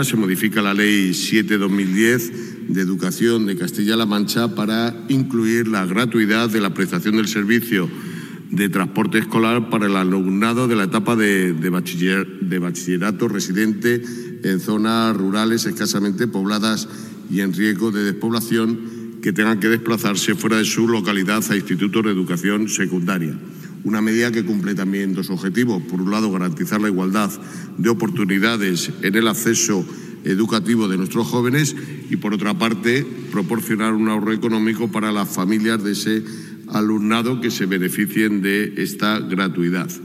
Consejería de Hacienda, Administraciones Públicas y Transformación Digital Jueves, 10 Abril 2025 - 1:30pm Durante su intervención en el Pleno celebrado este jueves en las Cortes de Castilla-La Mancha, el consejero ha precisado las diferentes actuaciones que contempla la ley, entre ellas algunas en materia educativa encaminadas a reforzar las políticas sociales.